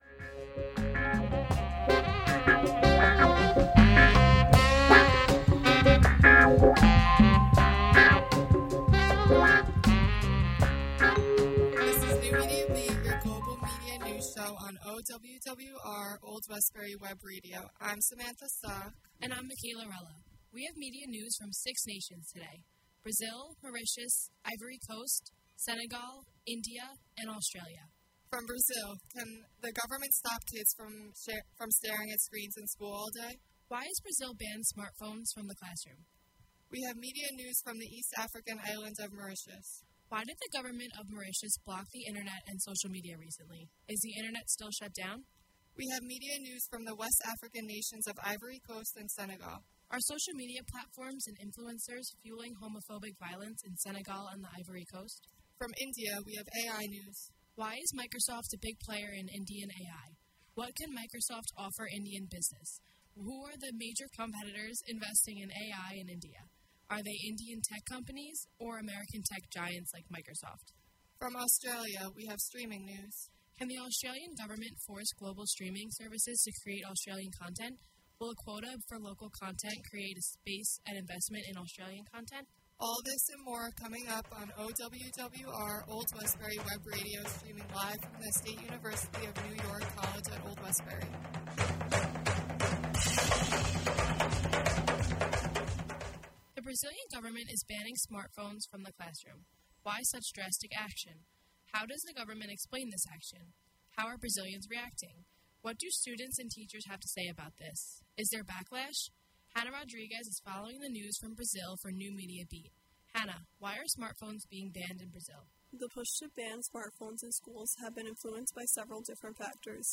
New Media Beat covers media news from around the world. NMB is a production of Media Studies students from the SUNY College @ Old Westbury.
The NMB Podcast streams live on Old Westbury Web Radio Thursdays from 10:15-11:15 AM EST. Can’t listen live?